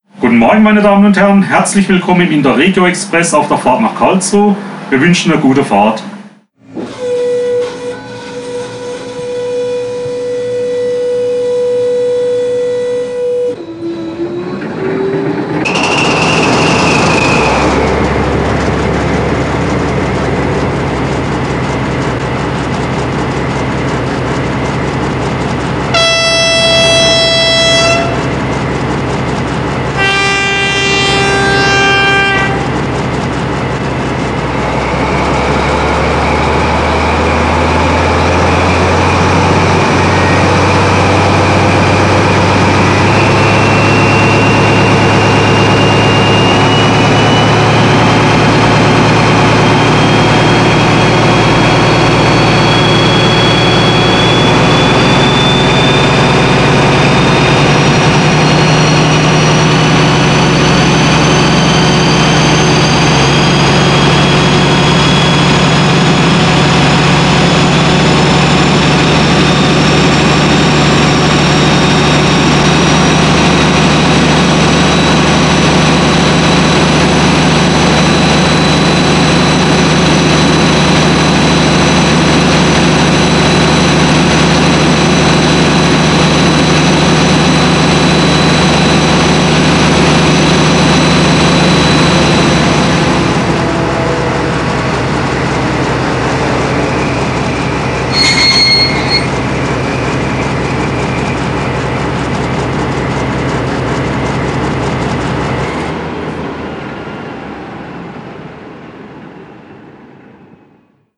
• Besondere Klangerlebnisse über 8 Kanäle mit einer Dauer von bis zu 8 Minuten